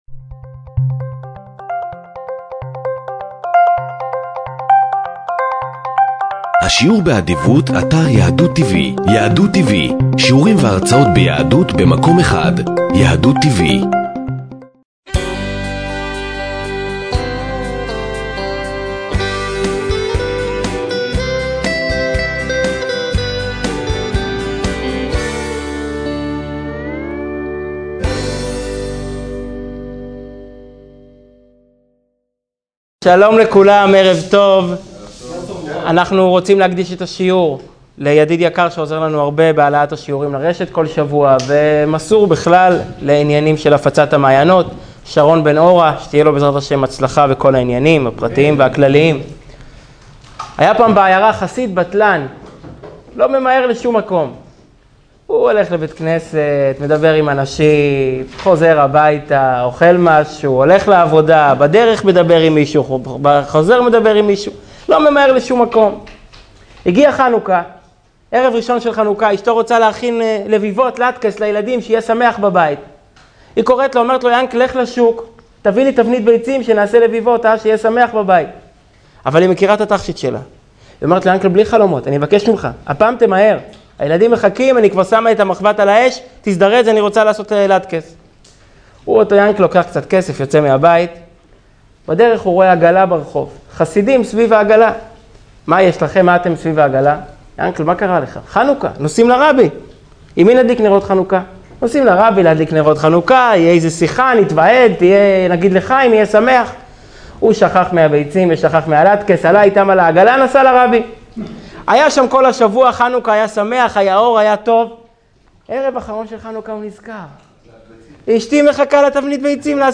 שיעור
שנמסר בביהכנ"ס חב"ד בראשל"צ